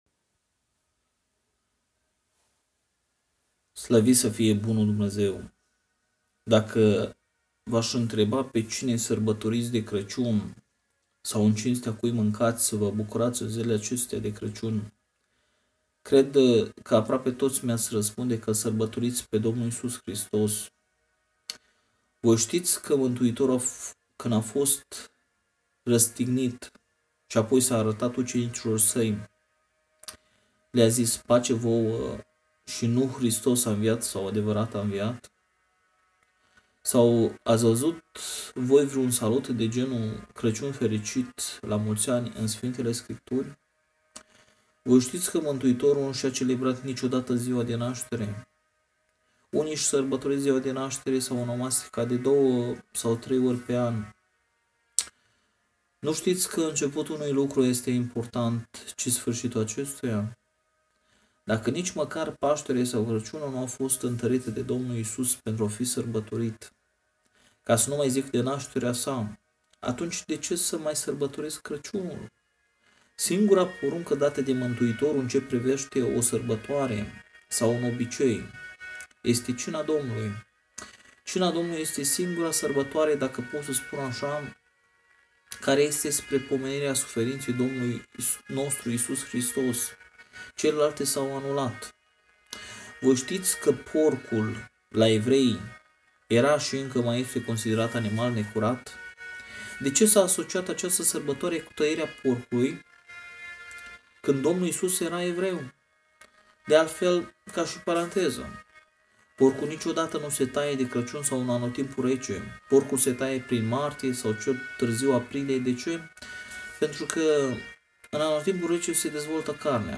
Cuprinde o selectie de predici audio si text care te ajuta sa intelegi de unde vii, cine esti si ce vrea Dumnezeu de la tine.